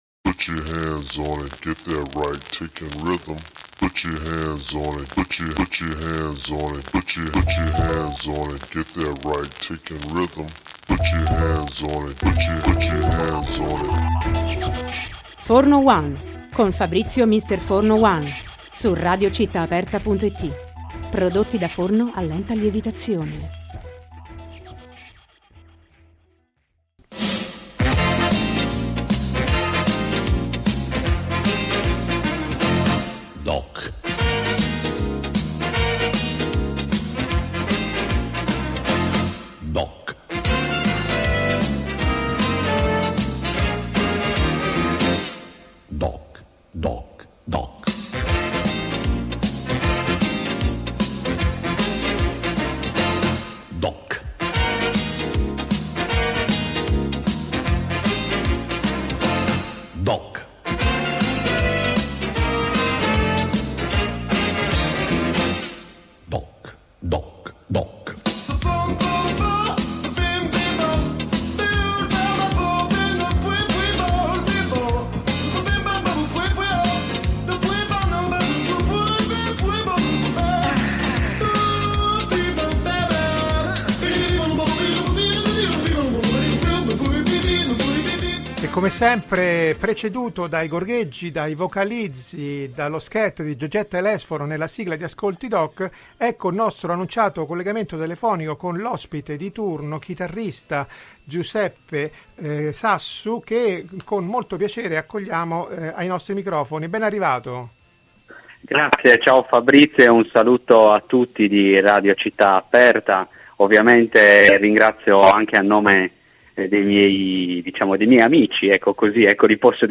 Intervista-DejaVu.mp3